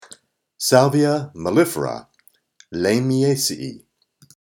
Pronunciation:
Sál-vi-a mel-lí-fera